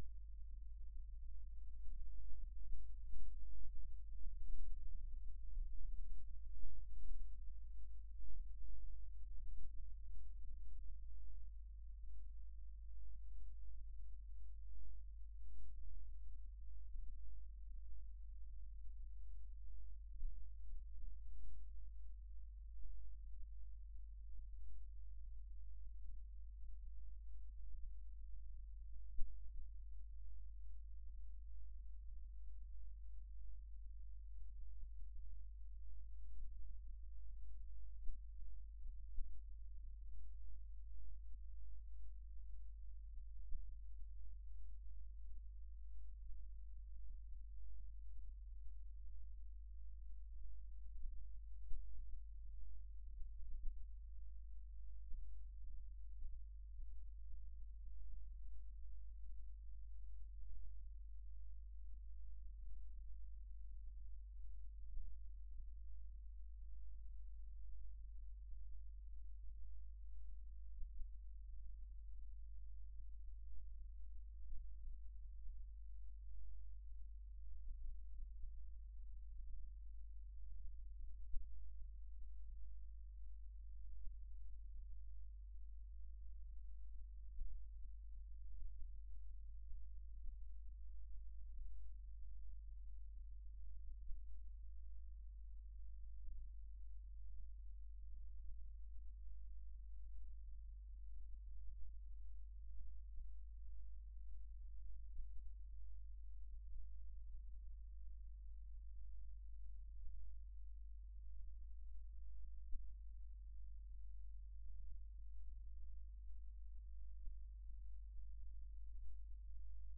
Wave file of earthquake, from 04:00 to 08:00, recorded with ICS101 induction coil:
1st Schumann resonance, wind, local storm and SRS (spectral Resonance Structures), but no magnetic anomaly detected coinciding with the event. The strange signal below 2 Hz is of local origin: we do not know what causes it but it seem to be associated with the presence of the wind.